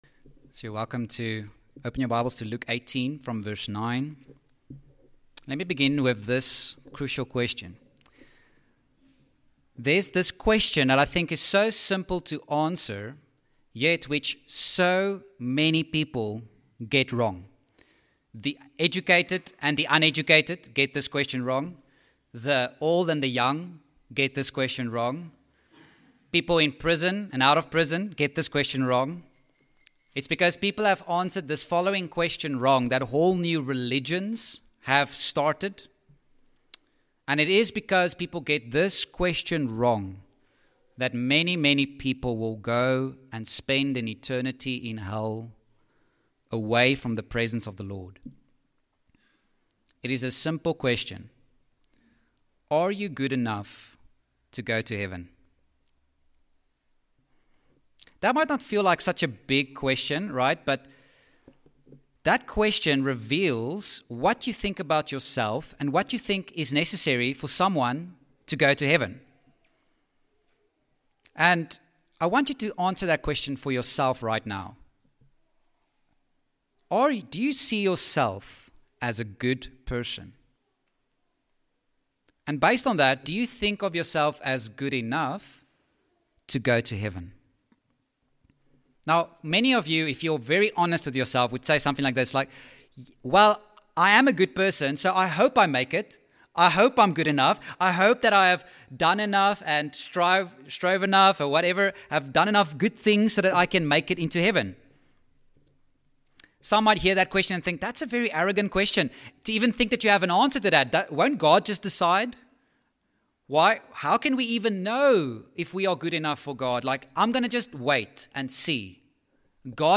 Sermons
Heritage Baptist Church Potchefstroom sermons